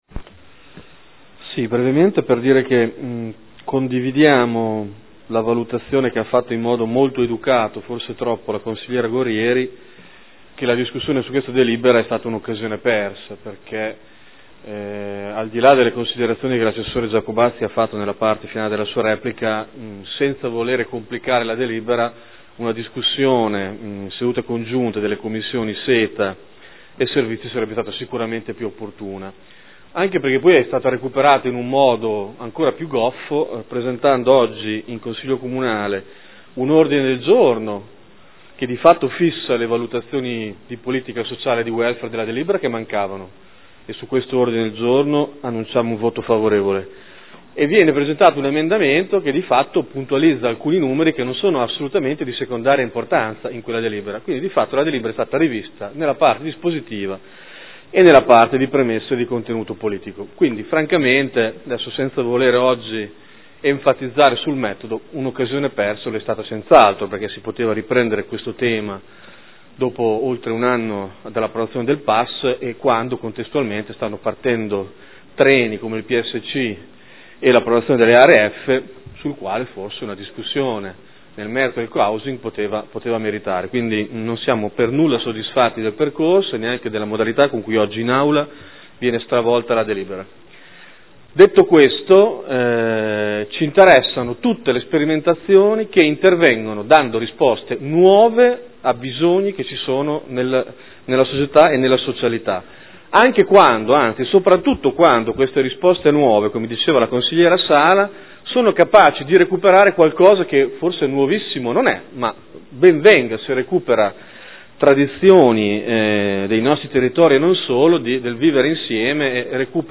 Seduta del 13/05/2013 Dichiarazione di voto.